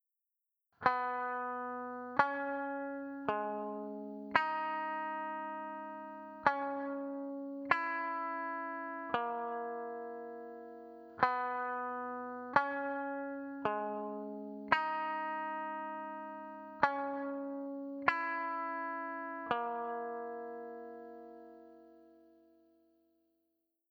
Delay Based Effects; Vibrato, Flange, and Delay